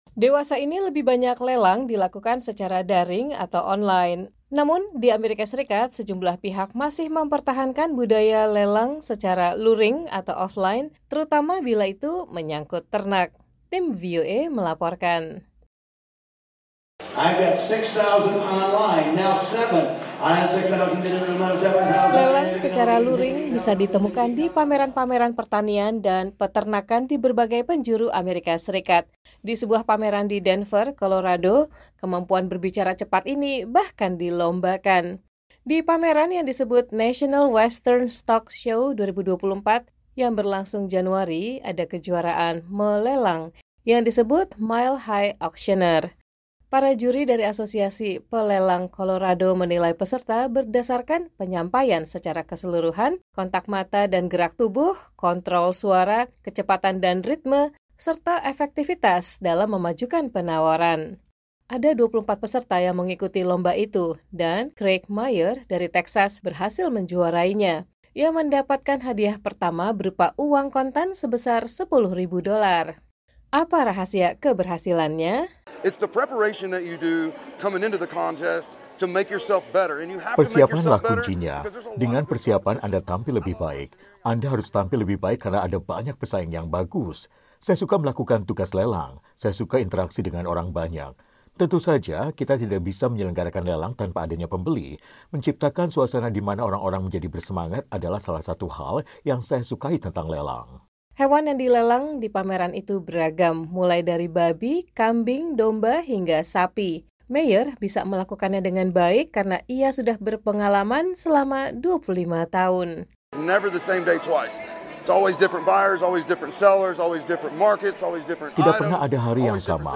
Juru lelang ternak bicara cepat dalam kejuaraan melelang "Mile High Auctioneer" di Denver, Colorado.
Di pameran yang disebut National Western Stock Show 2024, yang berlangsung Januari, ada kejuaraan melelang yang disebut Mile High Auctioneer.